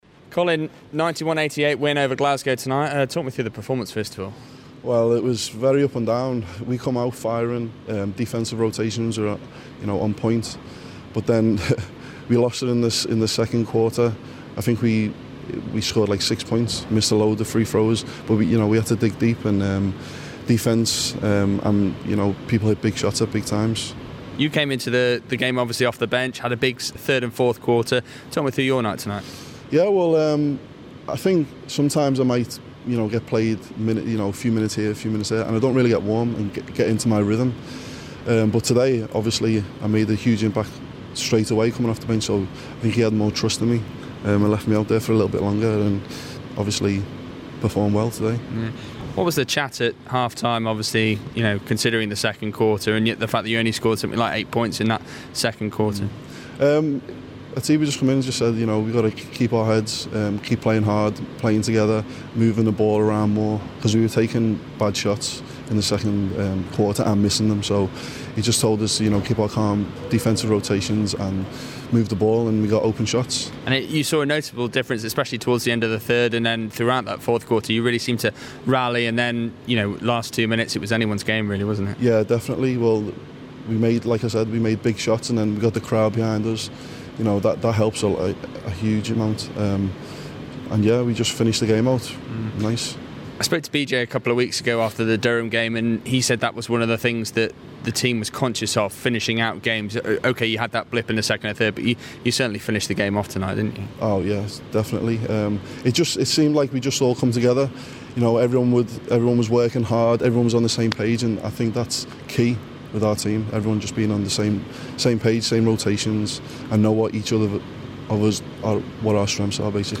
Football Heaven / INTERVIEW